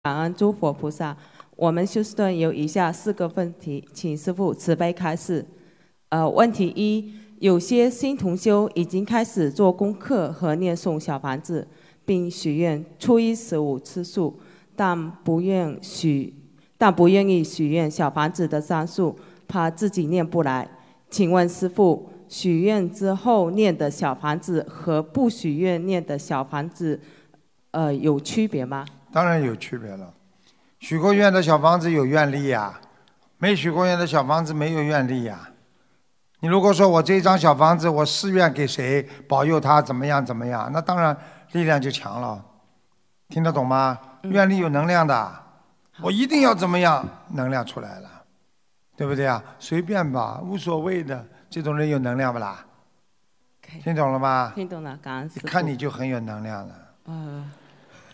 Pertanyaan di Seminar Dharma
Pertanyaan di Acara Pertemuan Umat Buddhis Sedunia di Auckland, 8 November 2019